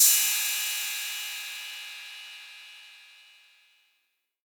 808CY_1_Tape_ST.wav